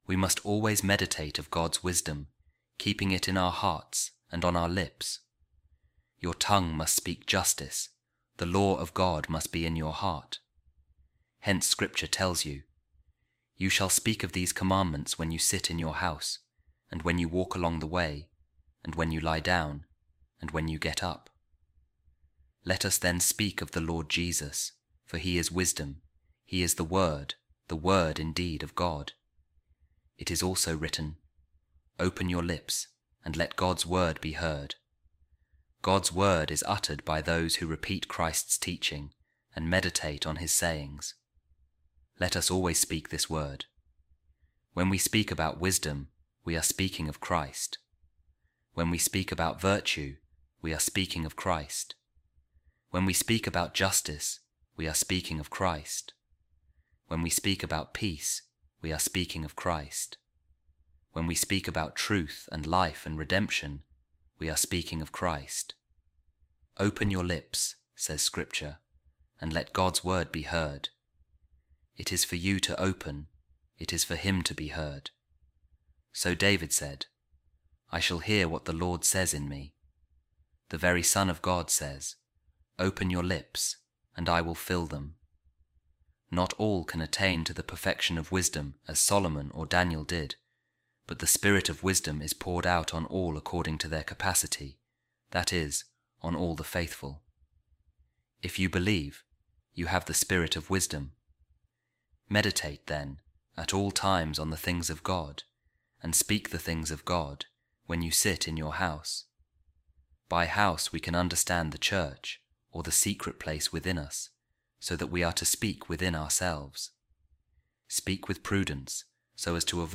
A Reading From The Explanations Of The Psalms By Saint Ambrose | Open Your Lips And Let God’s Word Be Heard